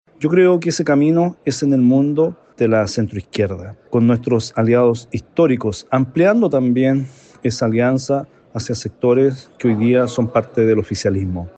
El parlamentario afirmó que la decisión que su partido tome el 26 de julio tiene que ver con qué alianzas políticas quiere construir la DC hacia el futuro.